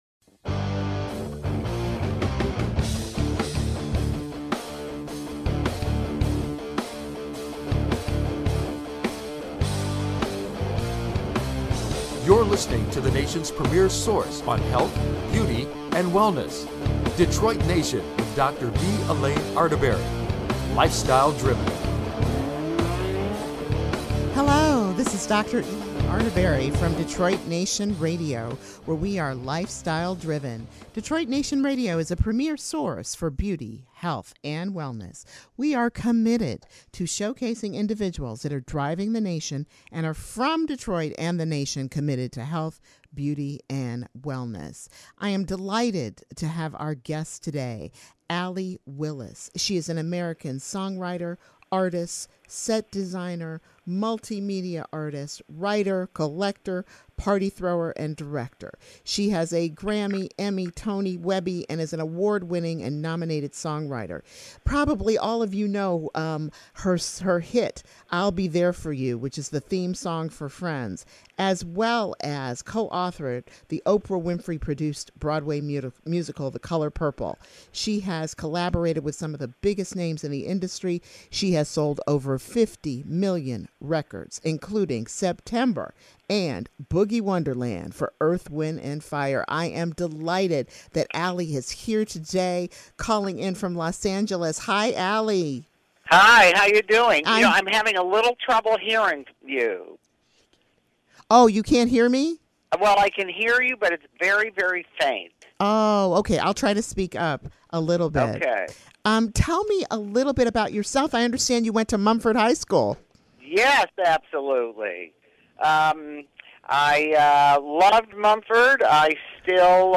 _DETROIT_NATION_RADIO_guest_Allee_Willis_2016_FEB.MP3